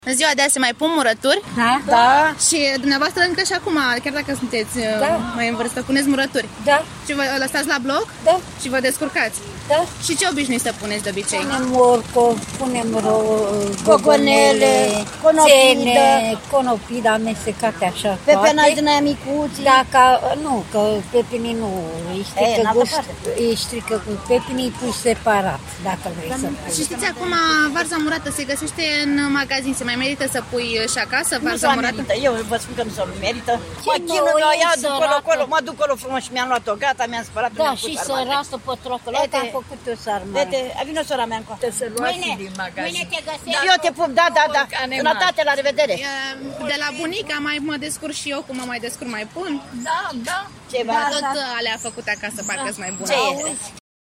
14sept-Vox-Muraturi-2.mp3